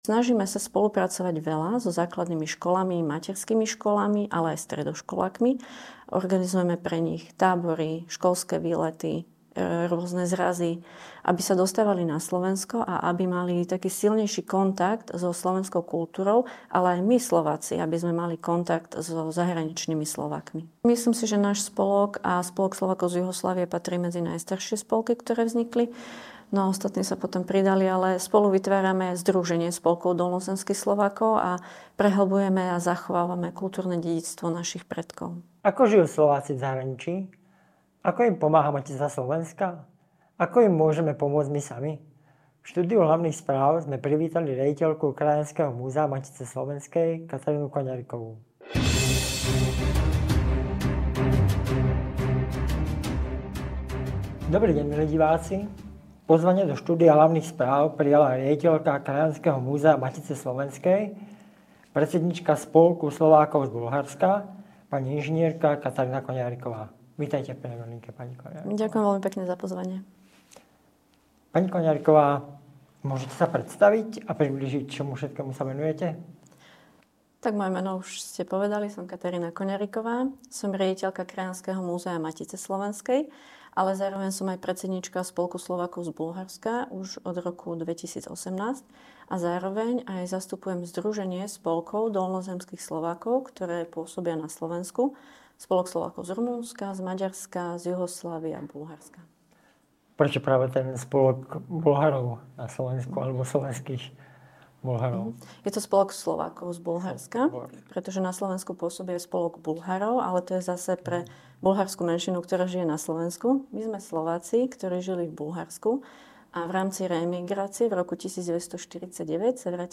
V štúdiu Hlavných Správ sme privítali